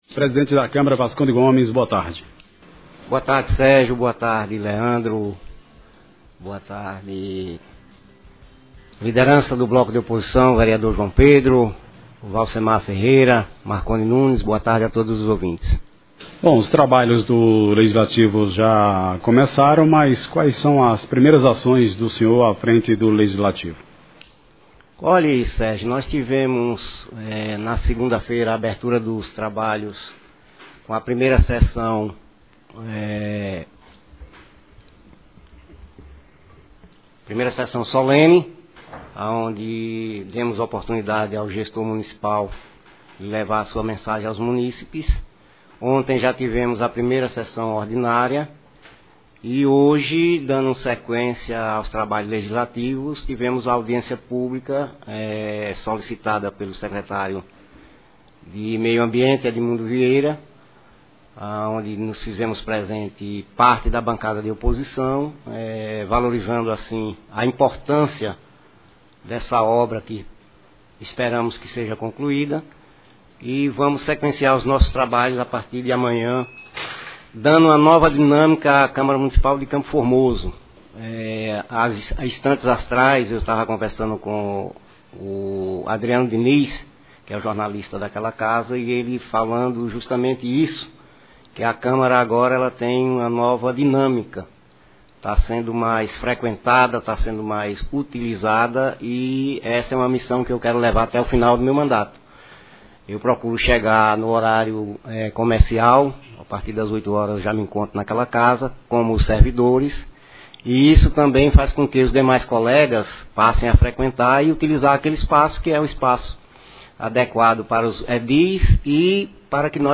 Entrevista- Presidente da câmara de vereadores de CFormoso Vasconde Gomes